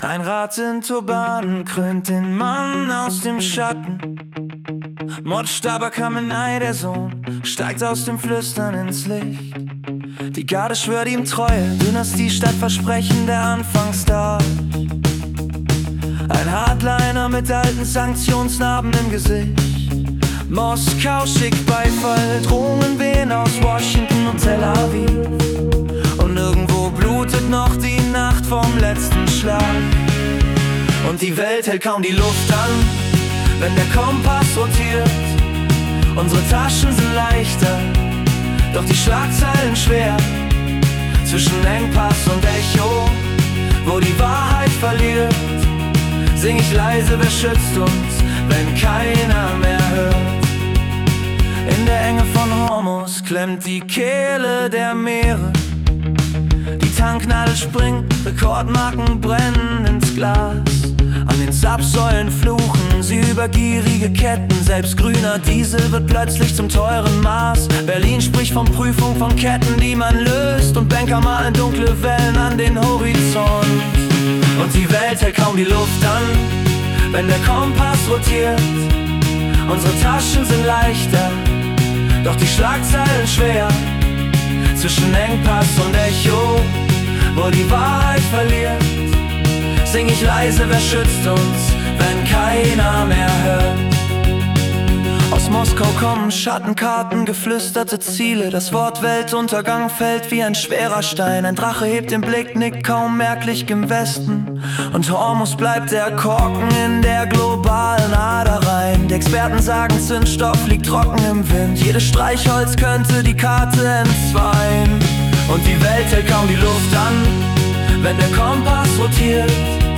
Die Nachrichten vom 11. März 2026 als Singer-Songwriter-Song interpretiert.